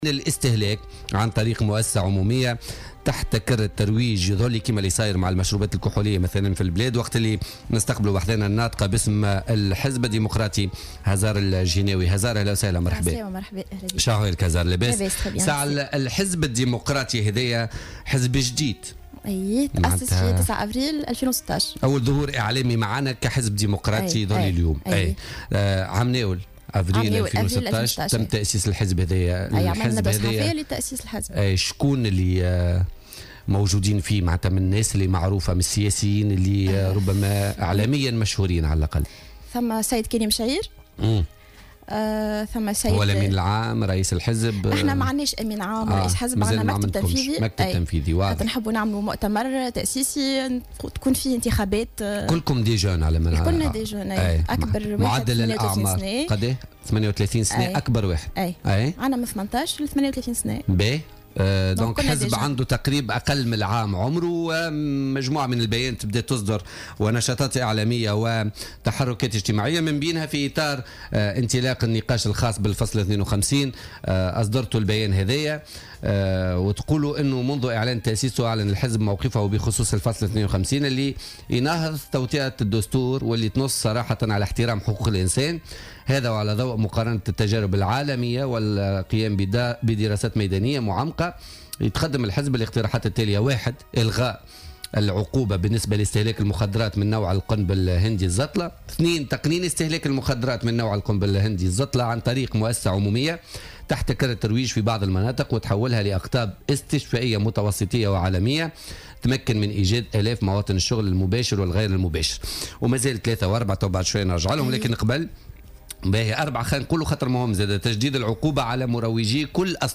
وأكدت ضيفة "بوليتيكا" على "الجوهرة أف أم" ماجاء في بيان أصدره حزبها مؤخرا والذي يدعو إلى إلغاء العقوبة في حق المستهلكين لمادة الزطلة (القنب الهندي) وتقنين عملية البيع وتشديد العقوبة على مروجي الزطلة.